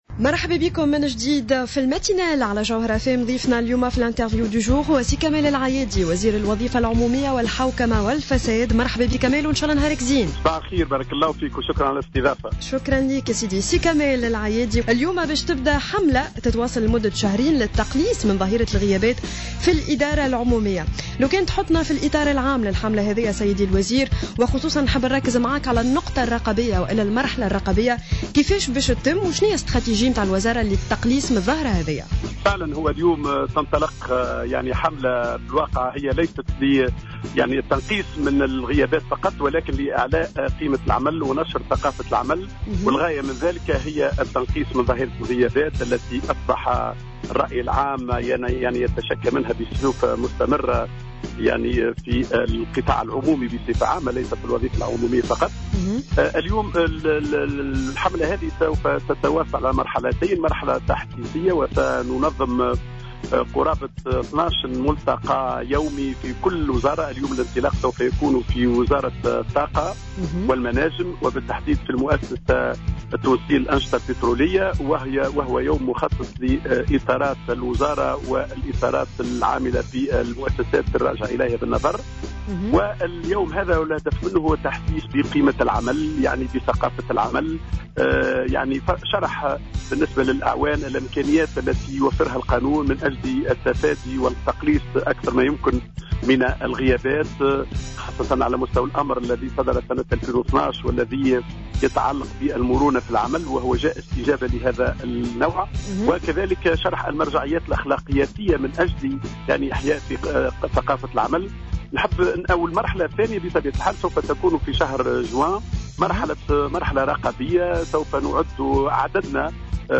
أكد وزير الوظيفة العمومية والحوكمة ومقاومة الفساد كمال العيادي في تصريح للجوهرة أف أم في برنامج صباح الورد لليوم الاثنين 9 ماي 2016 أن الحملة الوطنية للتقليص من الغيابات في الإدارات العمومية ستنطلق بداية من اليوم الاثنين.